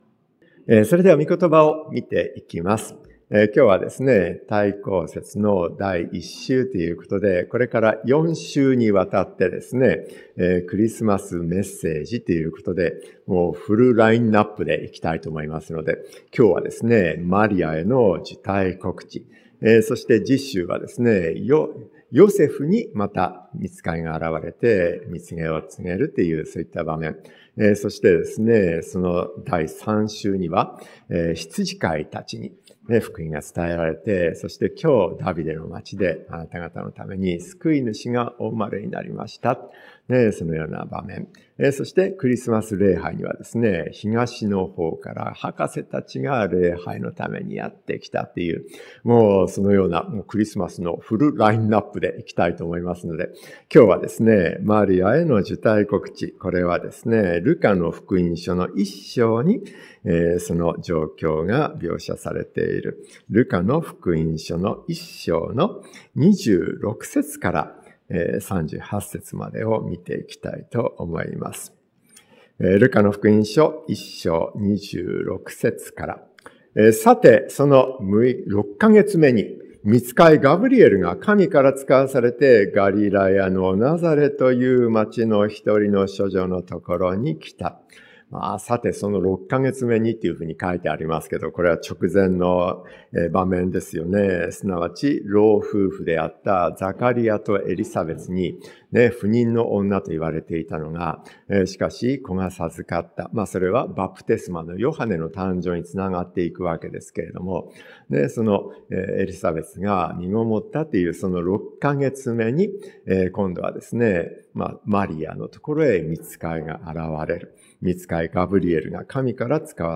マリアへの受胎告知 説教者